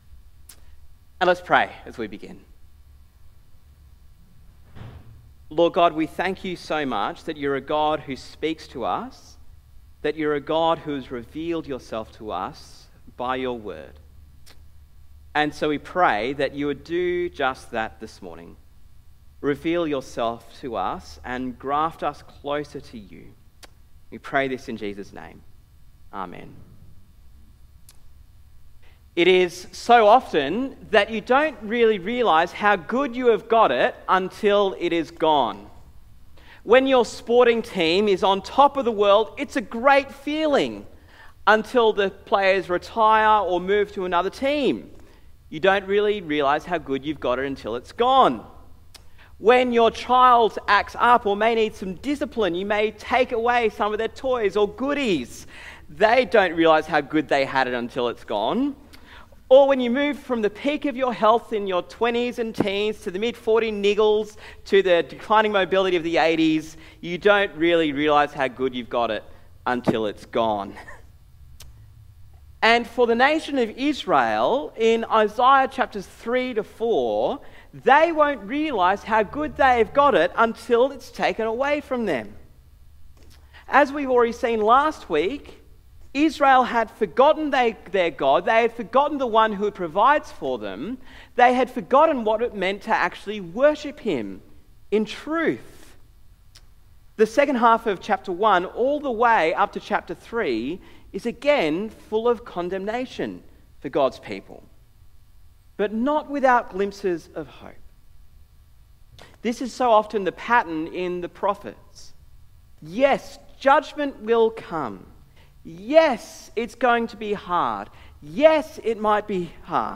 Sermon on Isaiah 3-4